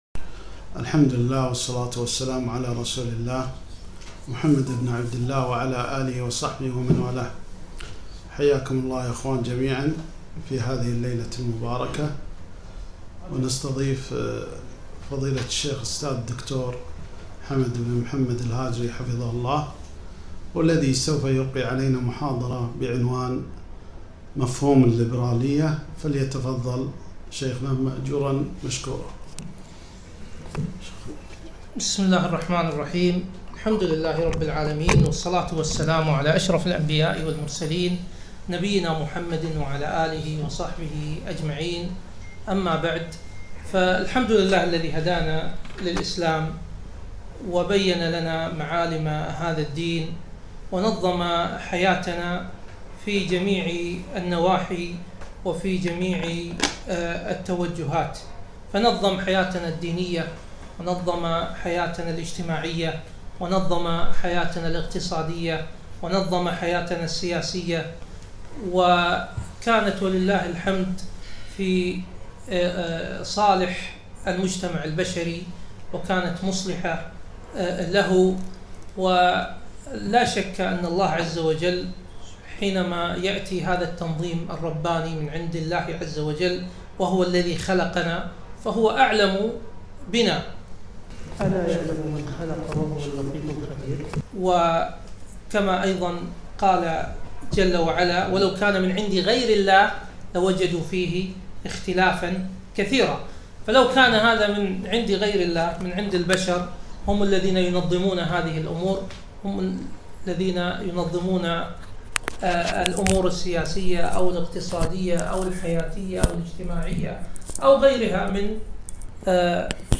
محاضرة - موقف الإسلام من الليبرالية - دروس الكويت